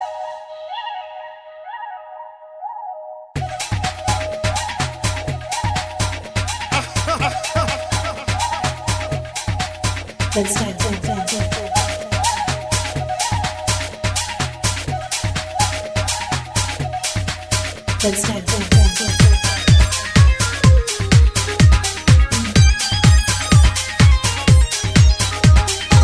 Category: Flute Ringtones